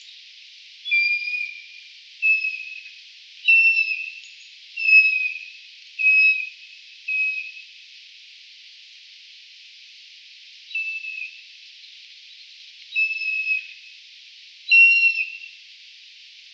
E 11,2663° - ALTITUDE: +300 m. - VOCALIZATION TYPE: flight calls.
No clear behavioural interactions are observed between them, apart from the vocalisations, which are likely produced by both individuals. - MIC: (Built in unidirectional microphone of Tascam DR100 mkIII)